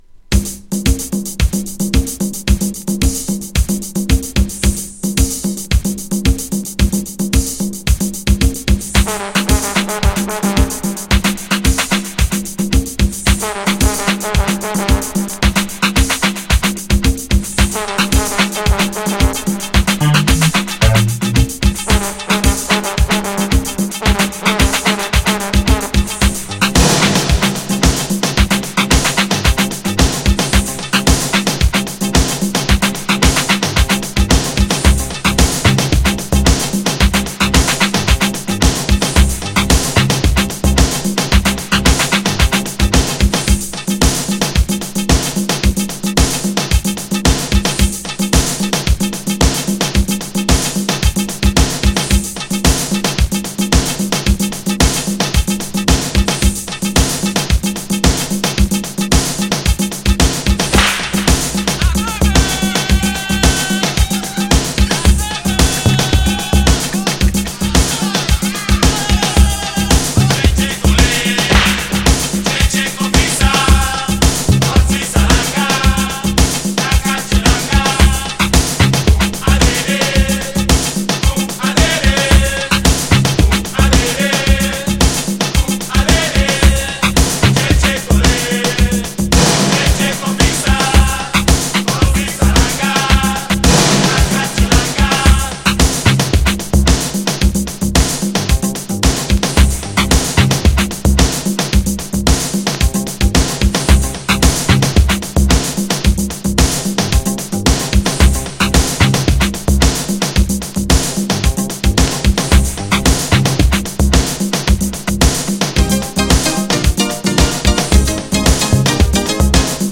２種類のDUBがめっちゃDEEP!!
GENRE Dance Classic
BPM 111〜115BPM